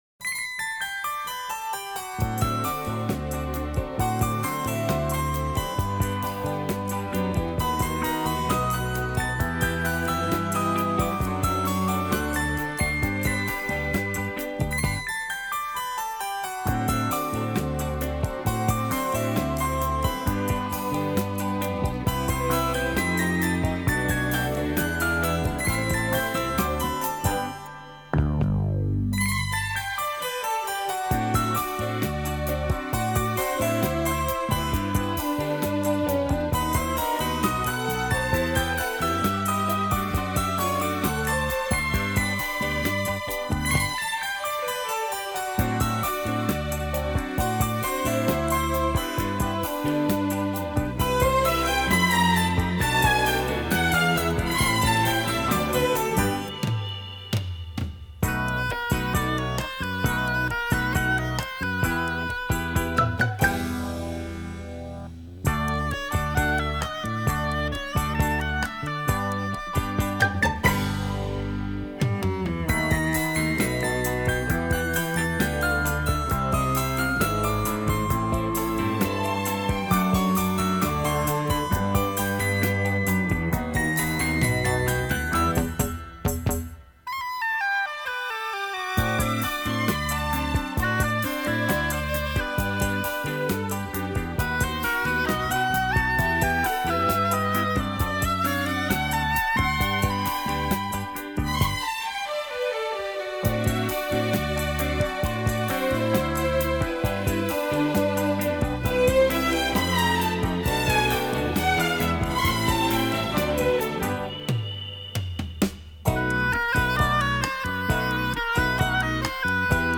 “清新华丽，浪漫迷人”